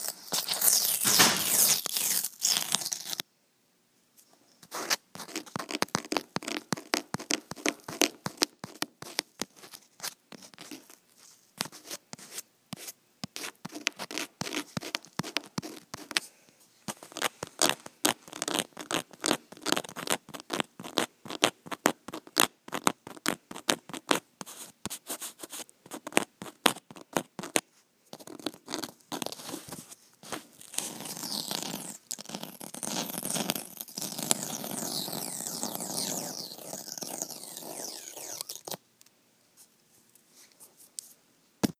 Field Recording #8
Identify the location of the clip – Emily Lowe Hall Room 216 on drafting desk
From this point you can hear the masking fluid peel off and stretch as it is removed from the paper. The masking fluid, once dry, is very elastic and stretches to be about four times longer than its original length when pulled. you can very distinctly hear the nature of this material as it is peeled back from the paper. It sort of sounds like stretchy tape being peeled back. As the clip continues you can hear the scratching and peeling sequence play once more.